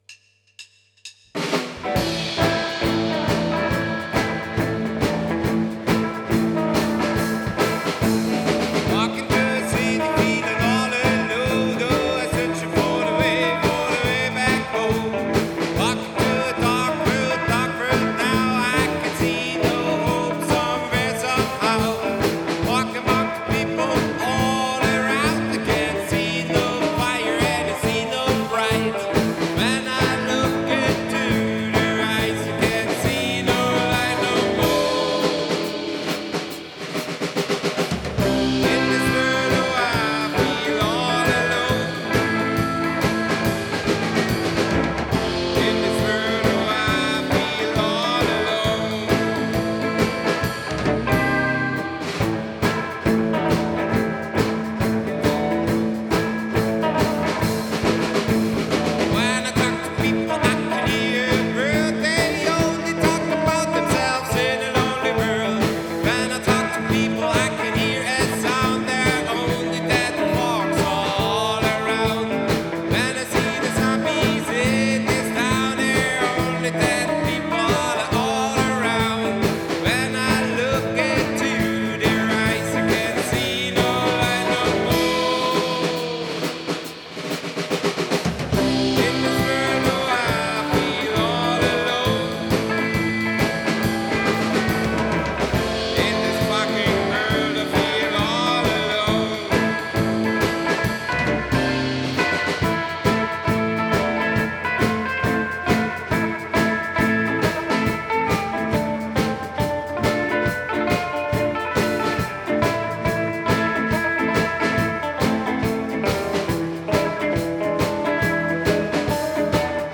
Genre: Psychobilly, Rockabilly, Country